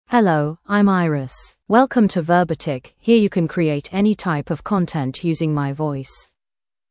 FemaleEnglish (United Kingdom)
Iris is a female AI voice for English (United Kingdom).
Voice sample
Iris delivers clear pronunciation with authentic United Kingdom English intonation, making your content sound professionally produced.